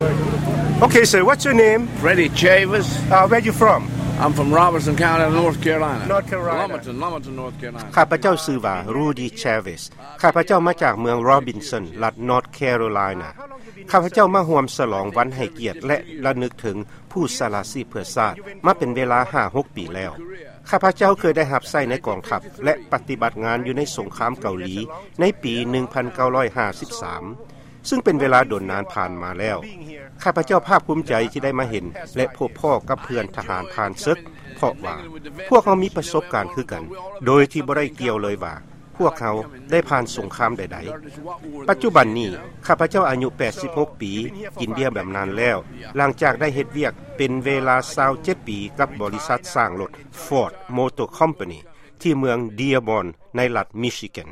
ຟັງການສໍາພາດ ທະຫານຜ່ານເສິກ